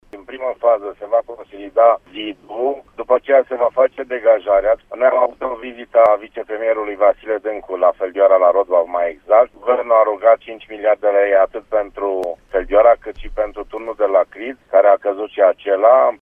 La lăcașul de cult din Rotbav a început consolidarea, după ce pe 19 februarie tunul bisericii evanghelice s-a prăbușit, spune primarul comunei Feldioara, Sorin Taus: